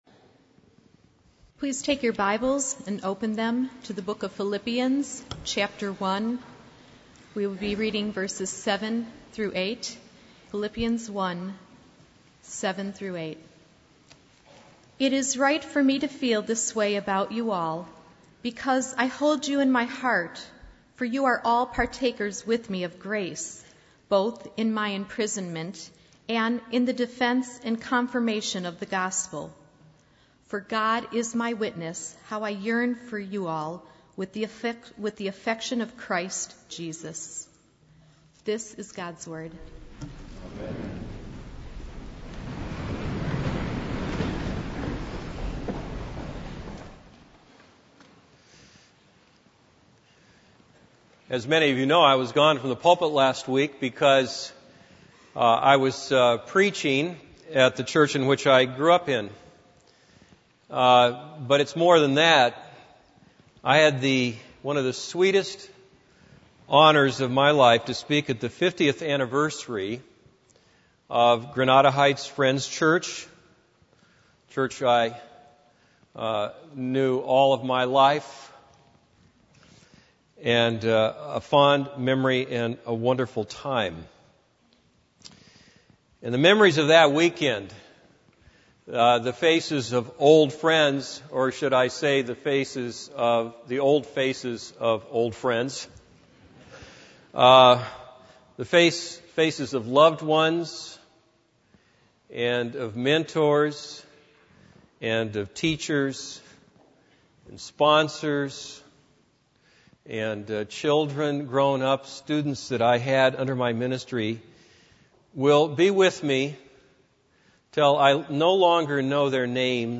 This is a sermon on Philippians 1:7-8.